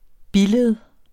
Udtale [ ˈbiˌleð ]